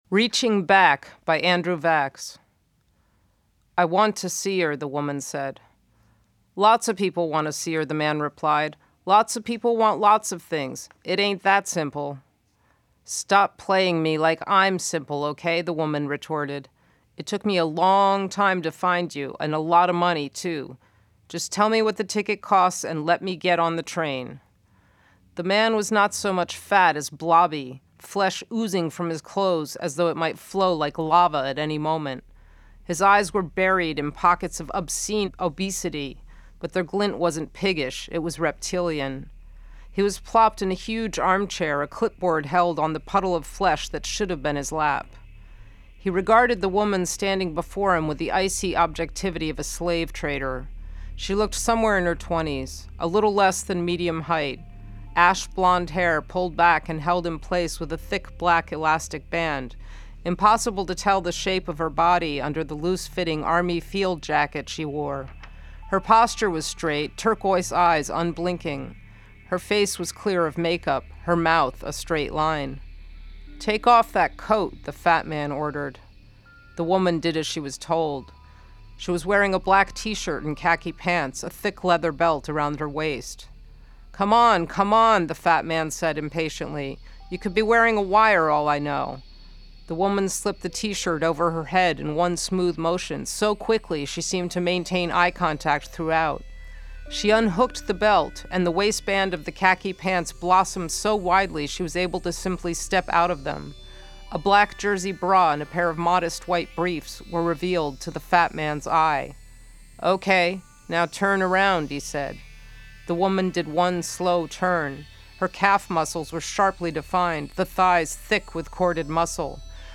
She is also making a series of charcoal sketches to illustrate some of the more graphic points of her novels, and has recorded an audio performance of the Andrew Vachss short story "Reaching Back."